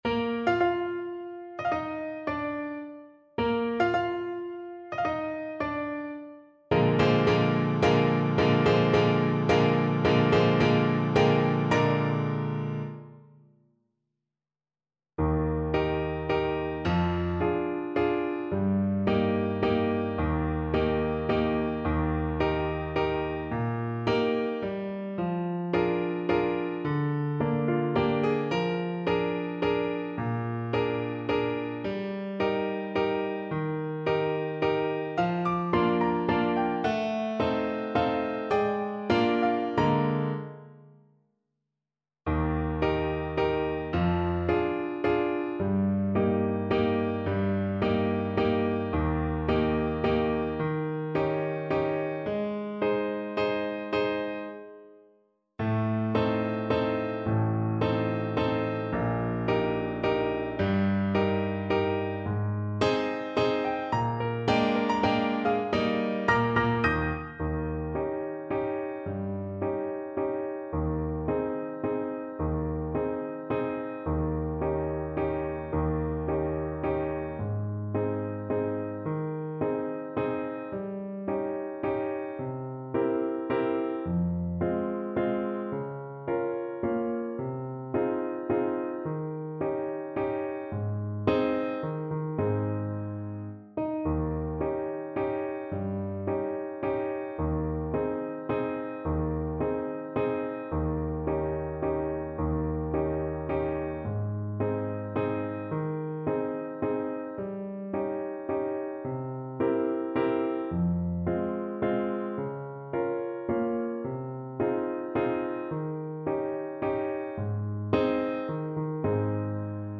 Play (or use space bar on your keyboard) Pause Music Playalong - Piano Accompaniment Playalong Band Accompaniment not yet available transpose reset tempo print settings full screen
Clarinet
Eb major (Sounding Pitch) F major (Clarinet in Bb) (View more Eb major Music for Clarinet )
Allegre brilhante =148
3/4 (View more 3/4 Music)
Classical (View more Classical Clarinet Music)
cecy_waltz_CL_kar3.mp3